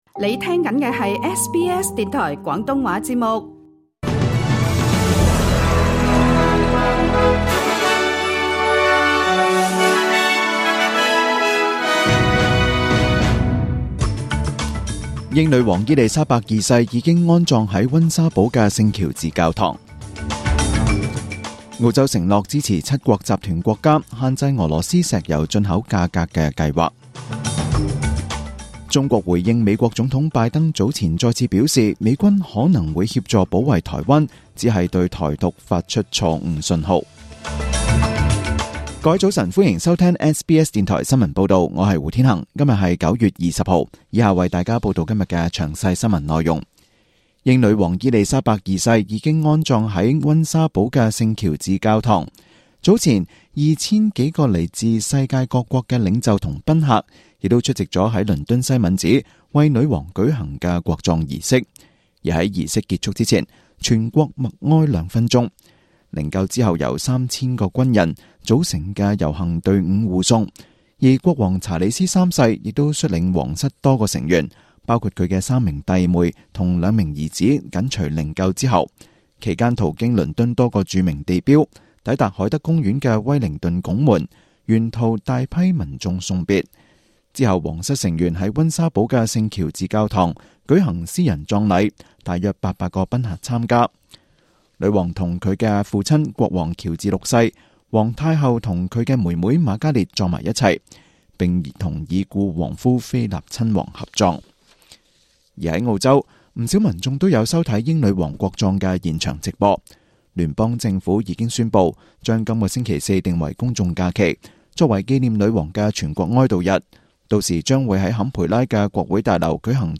SBS廣東話節目中文新聞 Source: SBS / SBS News